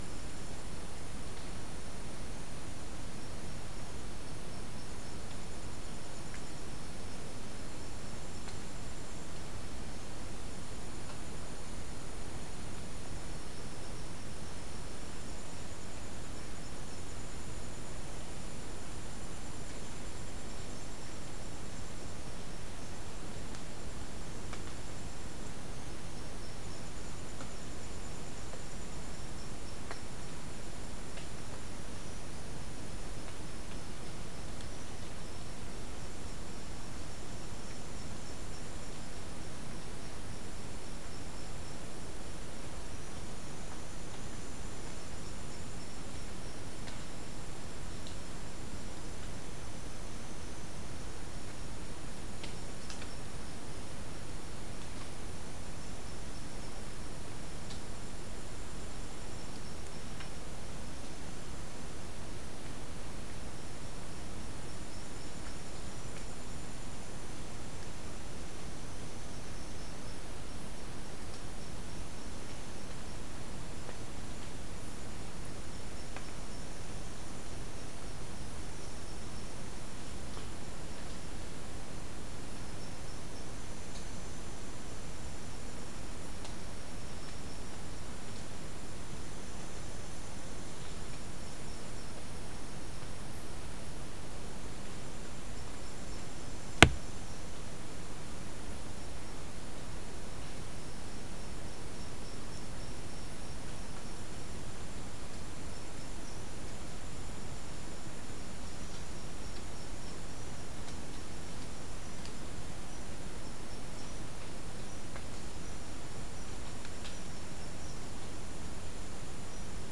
Non-specimen recording: Soundscape Recording Location: South America: Guyana: Rock Landing: 2
Recorder: SM3